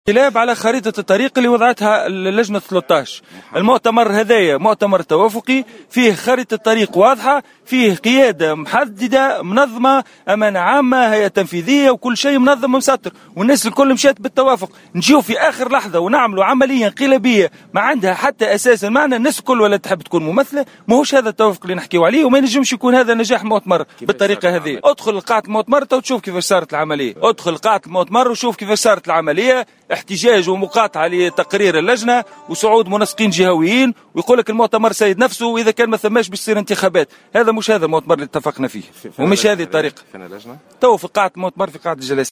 Hassouna Nasfi, député à l’assemblée des représentants du peuple et membre du comité des 13, a indiqué ce dimanche 10 janvier 2016 dans une déclaration accordée à Jawhara FM, que les participants au congrès de Nidaa Tounes à Sousse n’ont pas été fidèles à la charte précédemment fixée pour ce congrès.